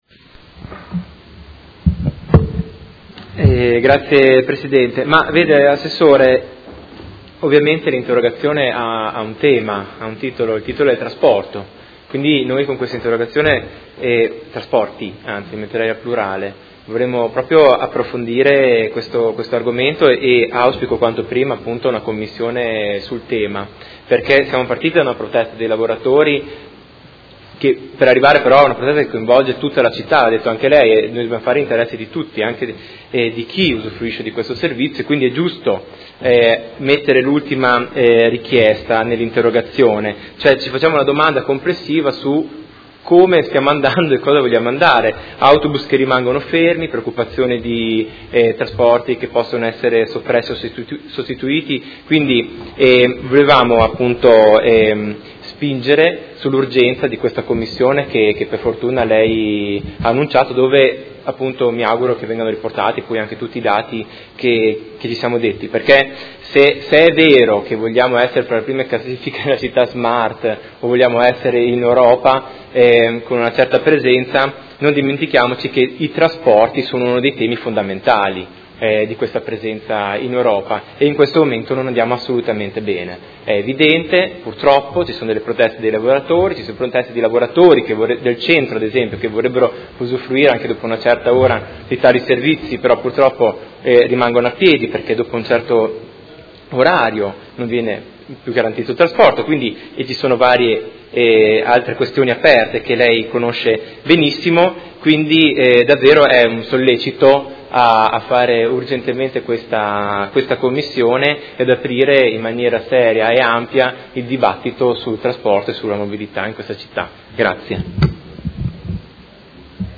Marco Chincarini — Sito Audio Consiglio Comunale
Seduta del 20/04/2016. Dibattito su interrogazione del Consigliere Cugusi (SEL), Chincarini e Campana (Per Me Modena) e Rocco (FAS – Sinistra italiana) avente per oggetto: Quale futuro per il trasporto pubblico locale?